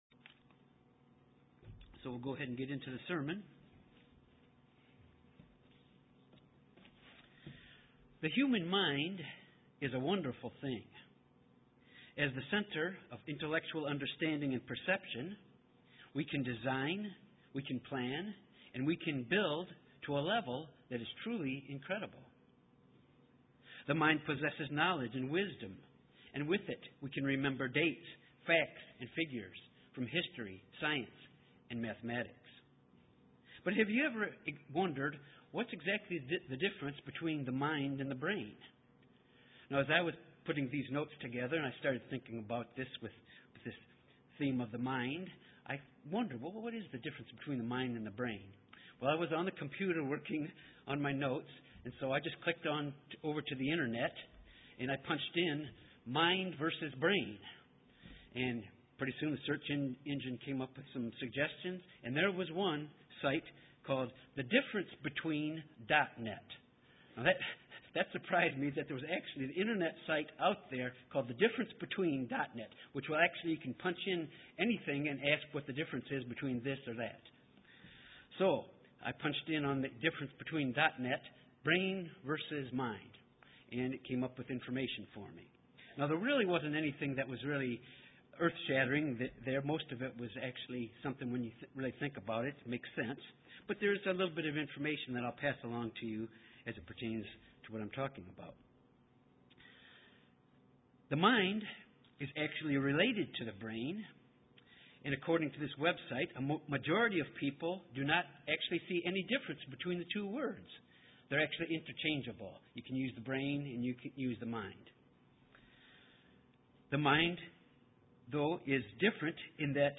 Sermons
Given in Little Rock, AR Jonesboro, AR